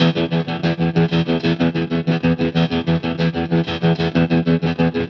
Trem Trance Guitar 01a.wav